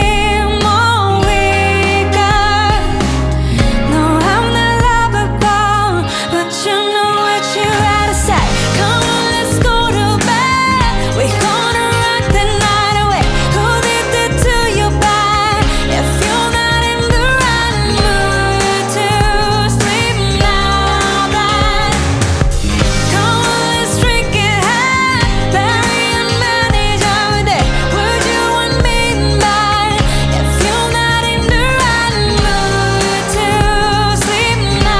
Singer Songwriter Pop K-Pop
Жанр: Поп музыка